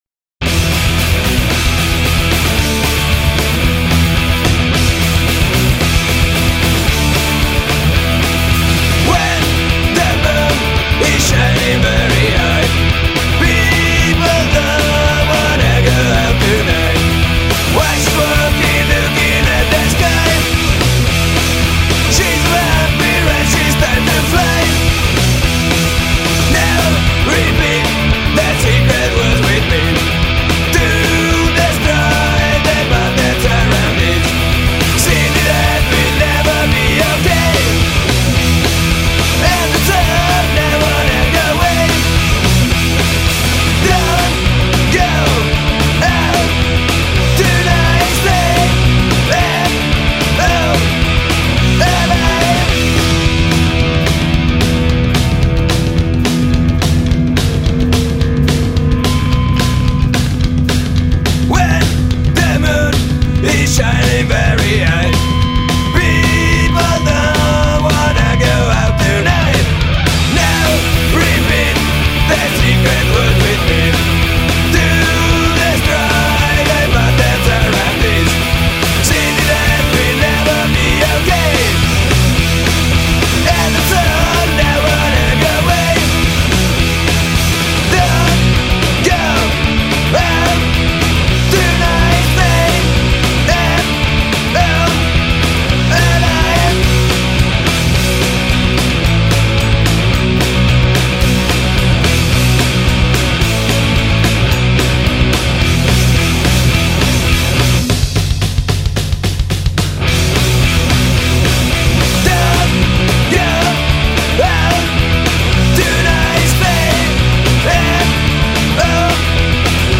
Voce e Chitarra
Basso e cori
Batteria
Chitarra e cori